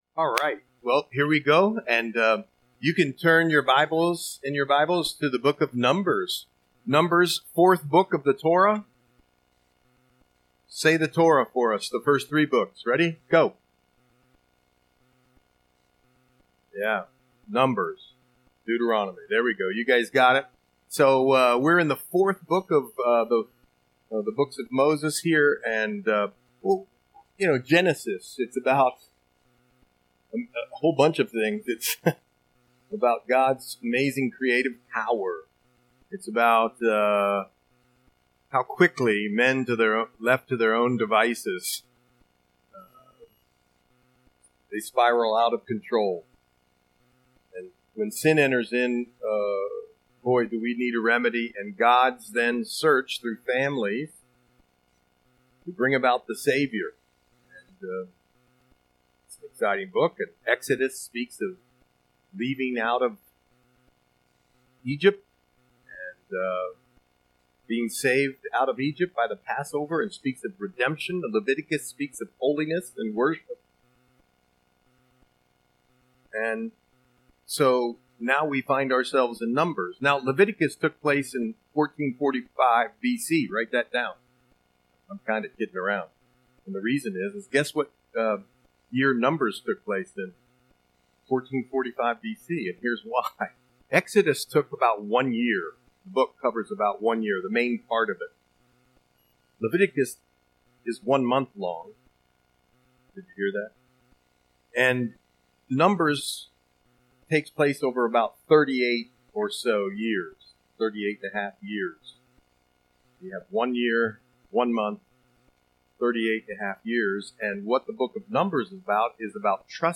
Audio Sermon - February 11, 2026